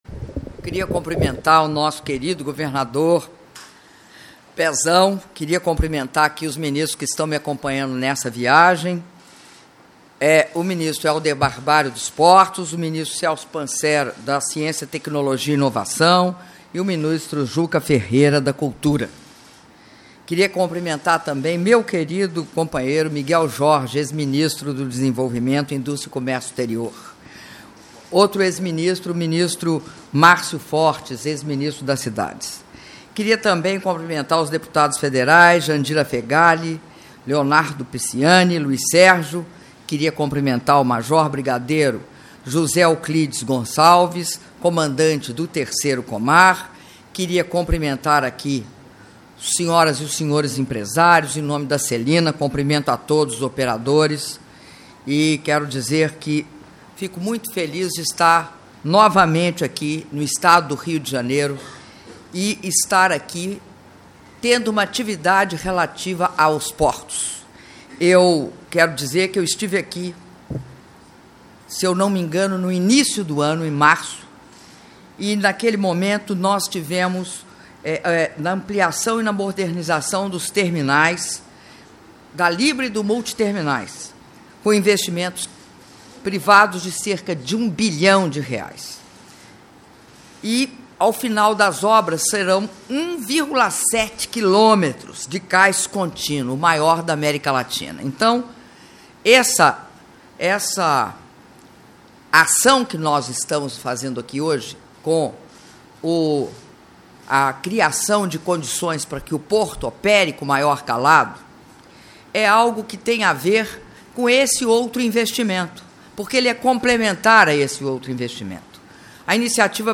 Áudio do discurso da Presidenta da República, Dilma Rousseff, durante cerimônia da ordem de início das obras de dragagem do Complexo Portuário do Rio de Janeiro - Rio de Janeiro/RJ (06min47s)